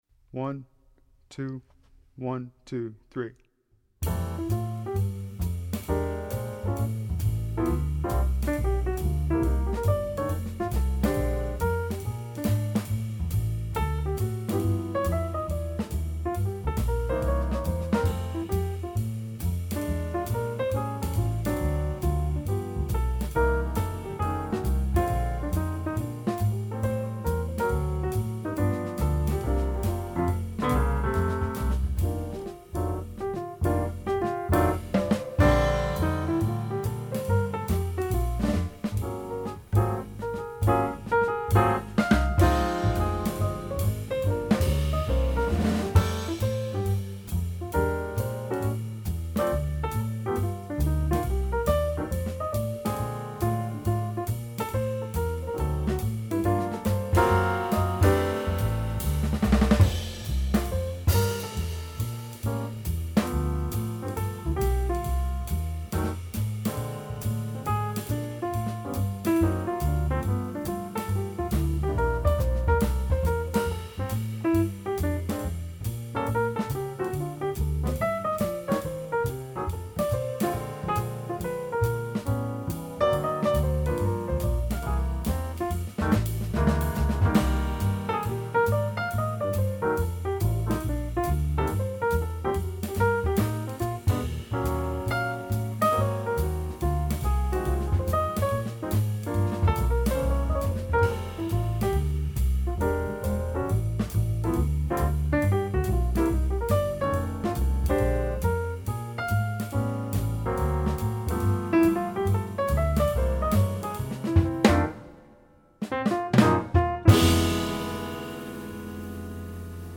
Piano Audition Track Bass Guitar
F22_Audition_Piano_track.mp3